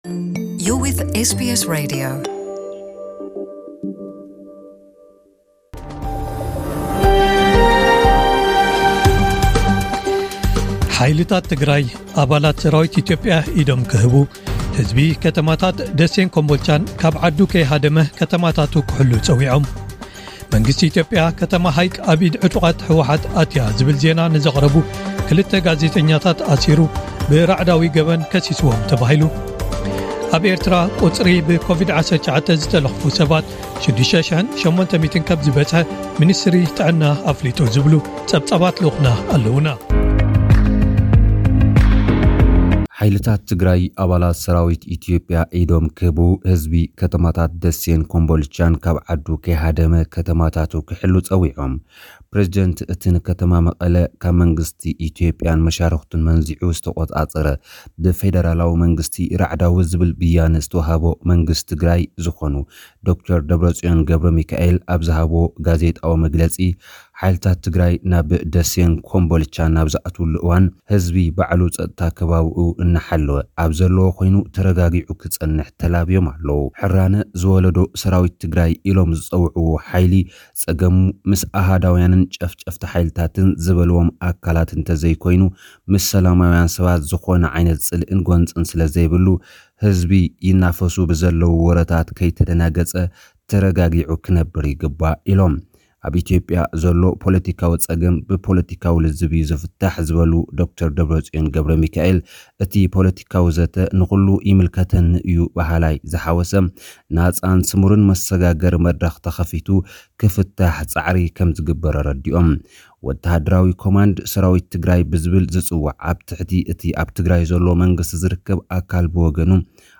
ጸብጻባት ዜና